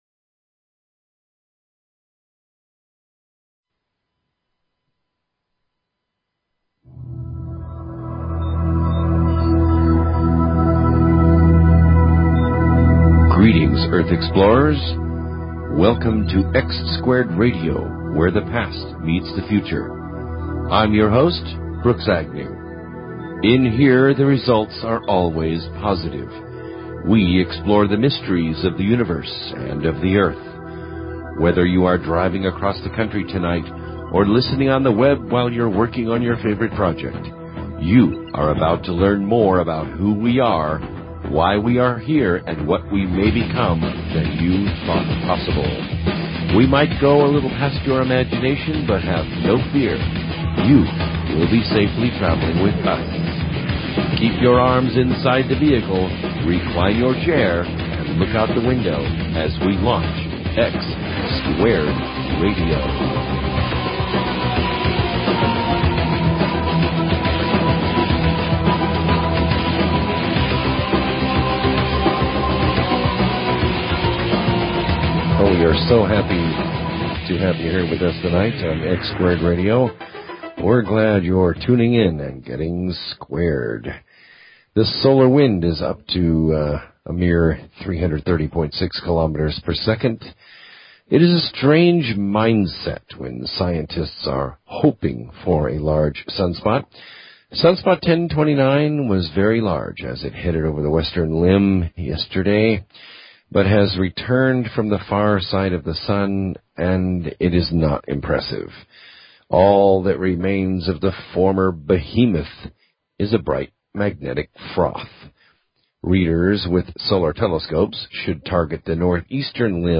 Talk Show Episode, Audio Podcast, X-Squared_Radio and Courtesy of BBS Radio on , show guests , about , categorized as
The Movie "2012" was discussed. Many callers had not seen the movie, but had wonderful insights into the meaning and the power of the human soul.